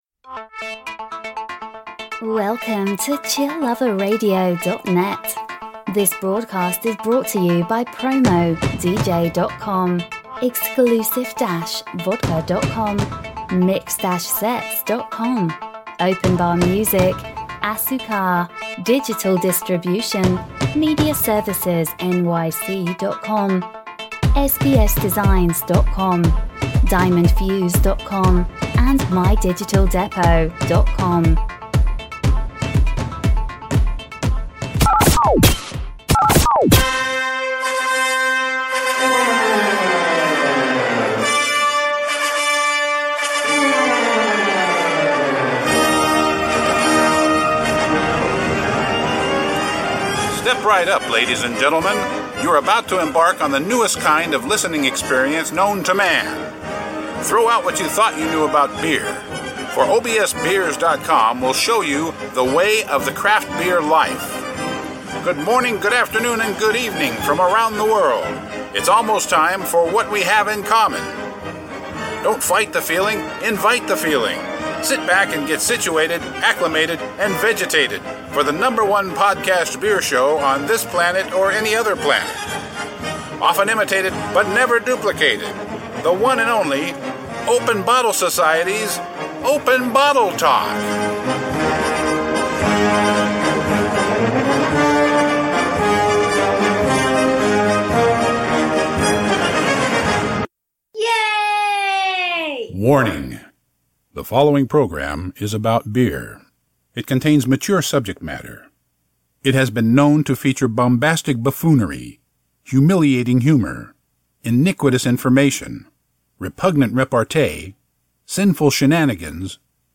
Styles: Beer Talk, Beer News, Beer, Craft Beers, Talk Show, Comedy